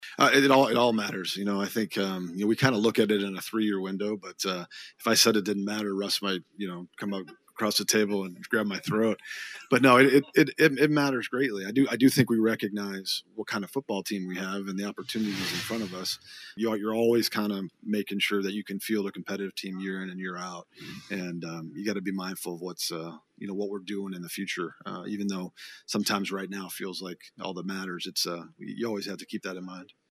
GM Brian Gutekunst meets the media in Indianapolis